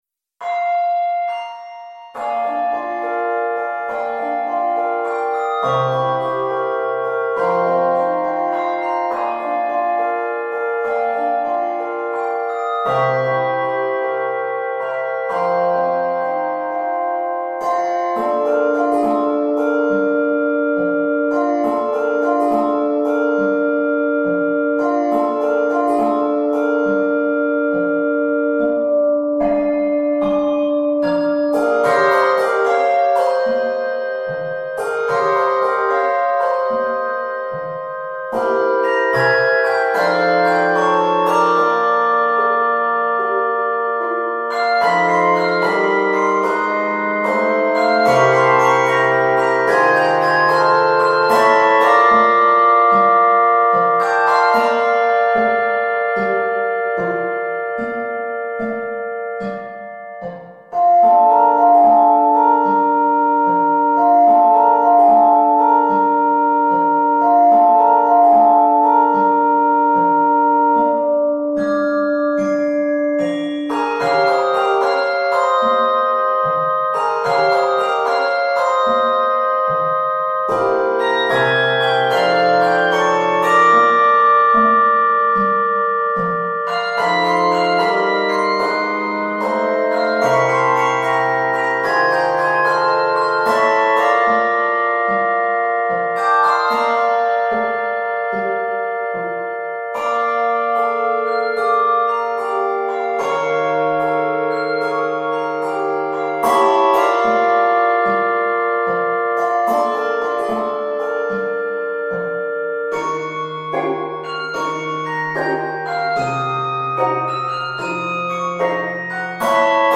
Key of Bb Major. 78 measures.